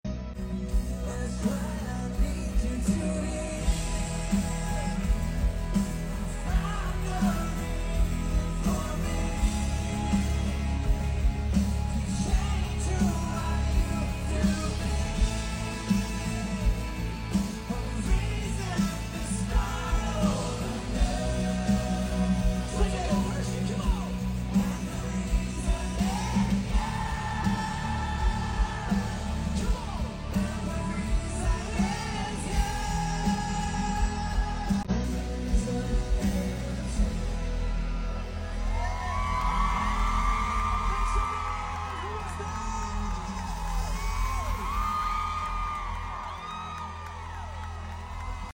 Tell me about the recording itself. in Pennsylvania